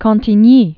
(käɴ-tē-nyē)